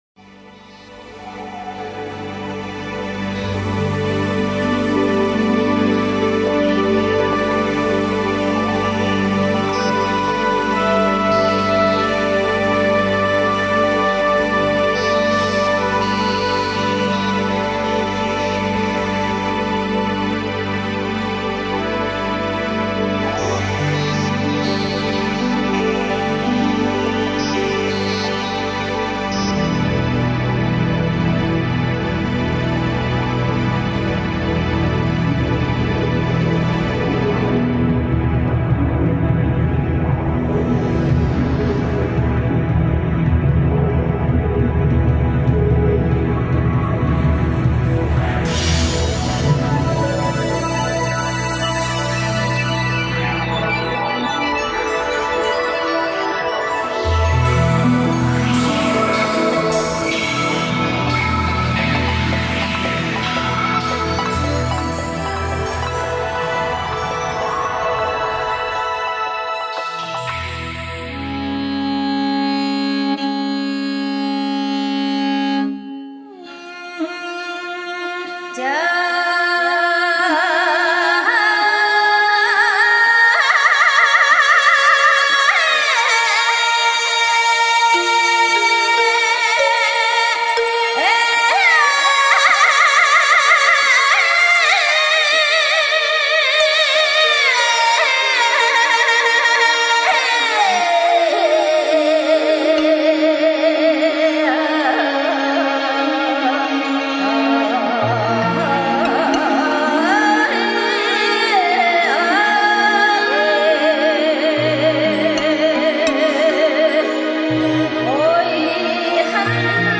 Norovbanzad - The sun over the placid world
(Mongolian traditional long song)